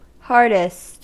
Ääntäminen
Ääntäminen US Haettu sana löytyi näillä lähdekielillä: englanti Käännöksiä ei löytynyt valitulle kohdekielelle. Hardest on sanan hard superlatiivi.